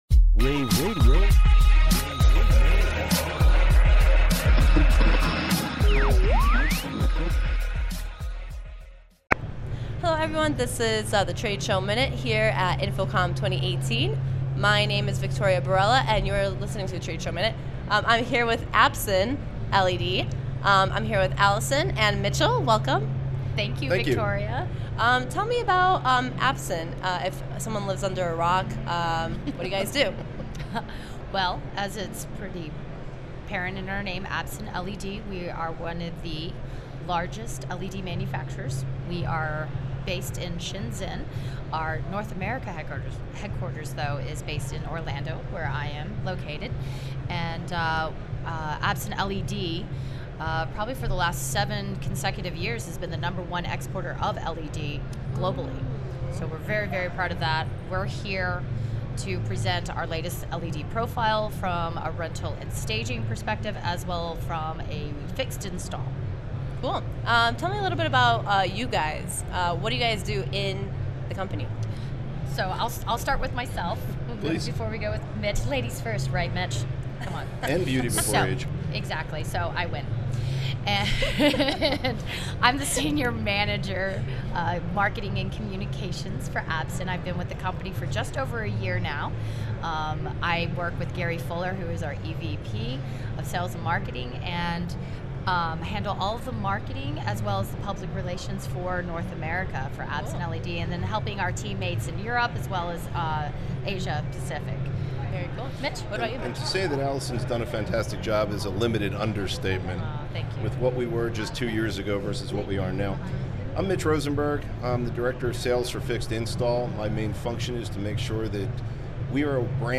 InfoComm Day2_showmin-225.mp3